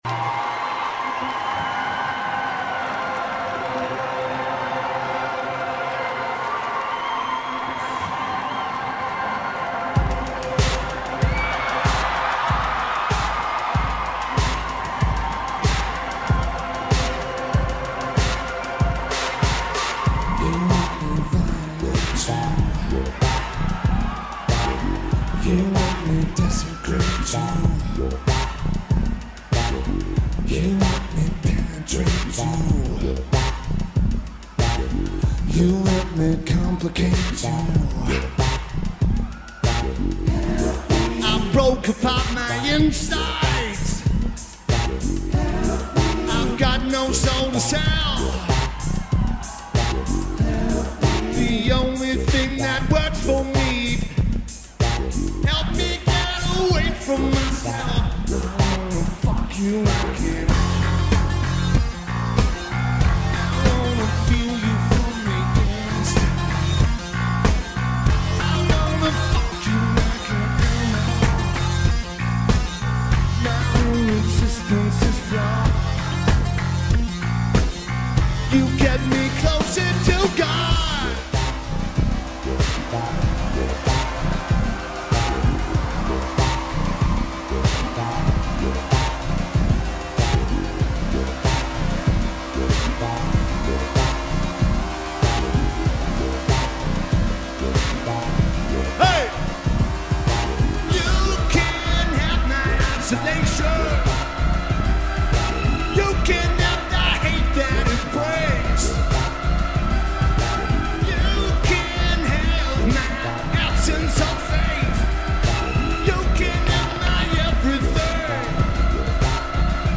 Lineage: Audio - SBD (YouTube Live Stream)
It's a soundboard feed, but the bass overloads from the mix.